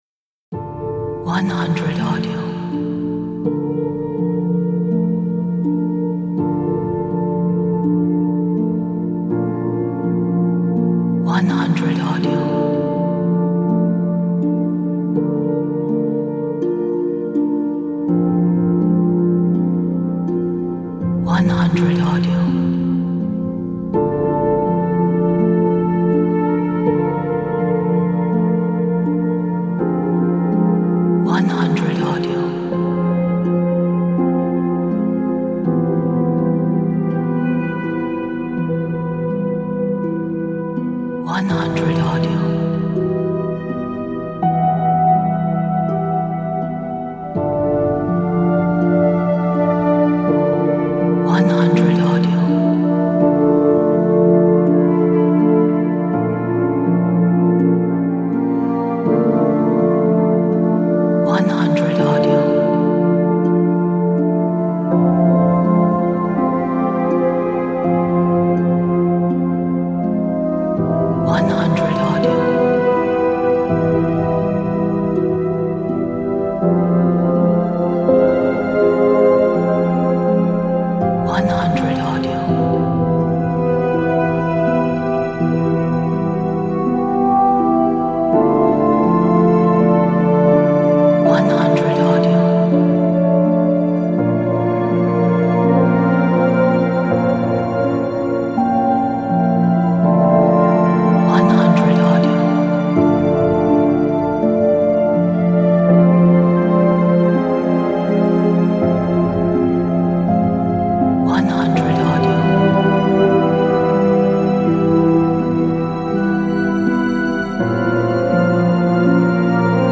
a dark, atmospheric, ambient - orchestral soundscape